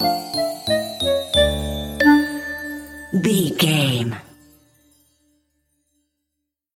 Aeolian/Minor
Slow
flute
oboe
piano
percussion
circus
goofy
comical
perky
Light hearted
quirky